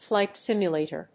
音節flíght sìmulator